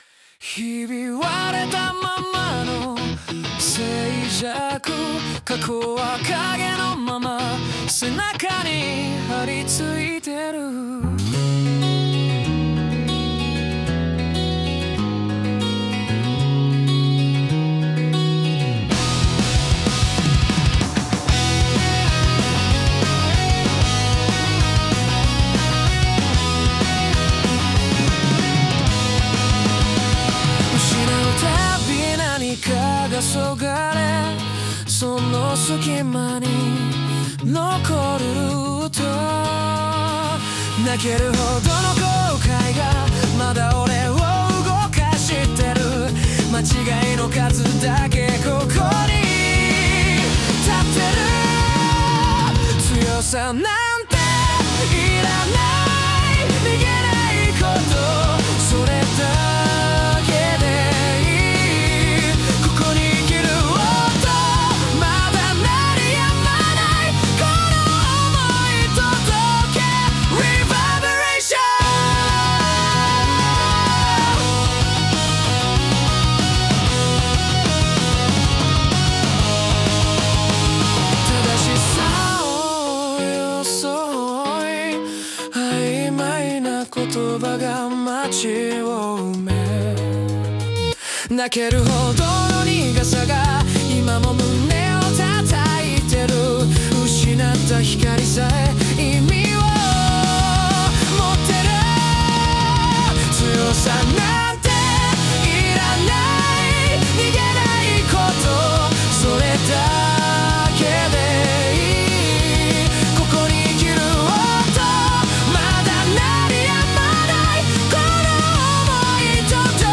男性ボーカル
イメージ：邦ロック,男性ボーカル,かっこいい,前向き,切ない